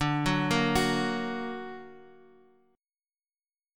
D+ chord {x 5 4 3 x 2} chord
D-Augmented-D-x,5,4,3,x,2-8.m4a